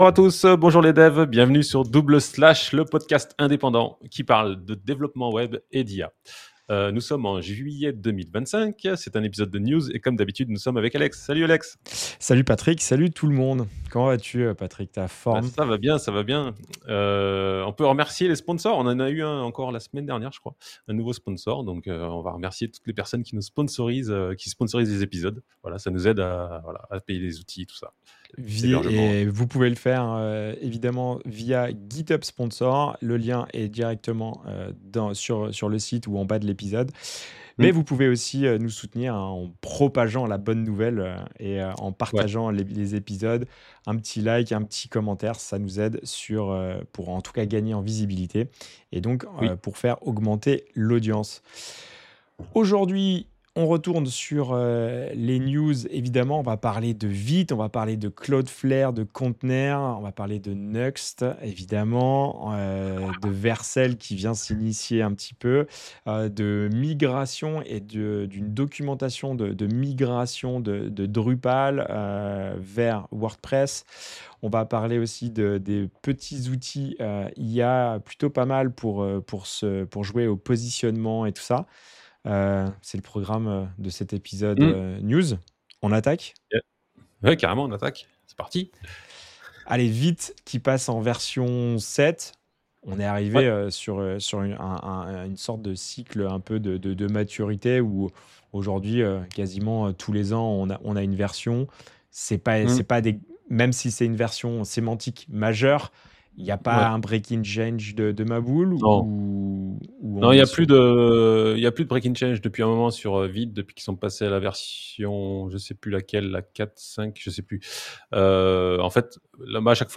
Double Slash, un podcast avec 2 animateurs qui se retrouvent pour discuter des outils et des techniques pour le développement moderne de site web et d’application web. Retrouvez-nous régulièrement pour parler de sujets variés tels que la JAMStack, l’accessibilité, l’écoconception, React JS, Vue JS et des retours d’expériences sur des implémentations.